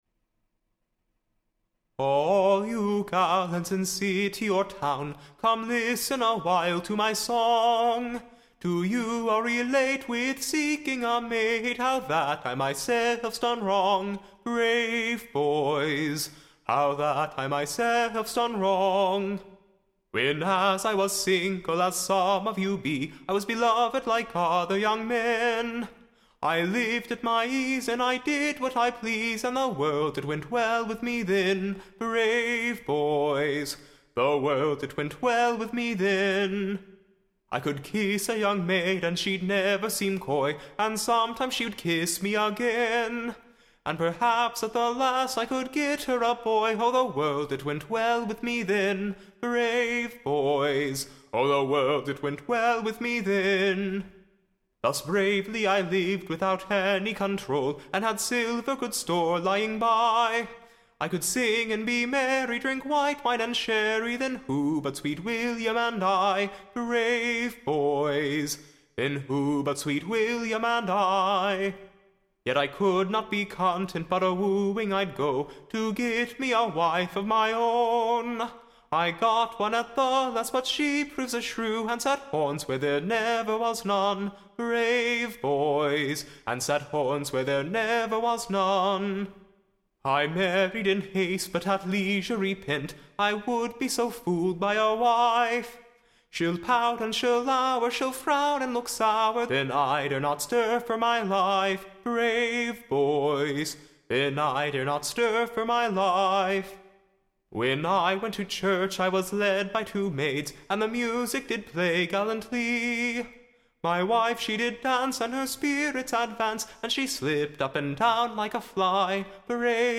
Ballad
Tune Imprint To the Tune of, Bonny bonny bird.